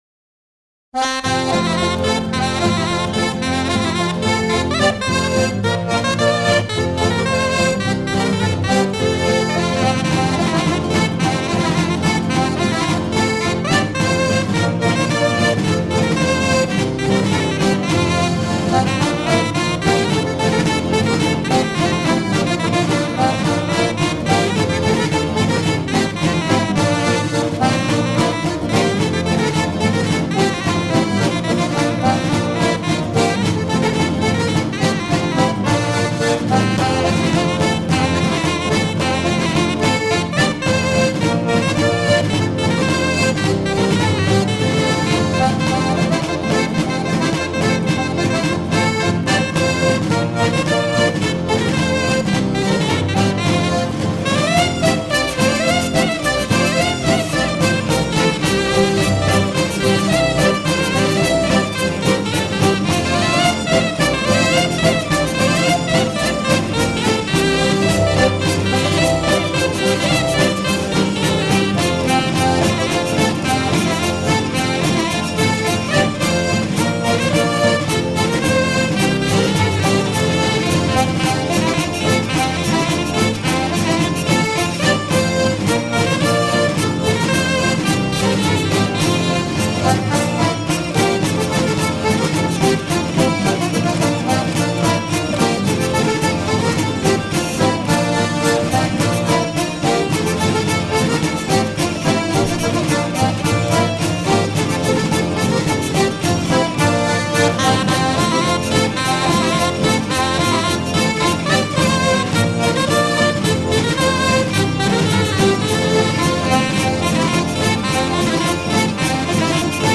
air de fête
danse : farandole
Pièce musicale éditée